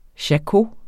Udtale [ ɕaˈko ]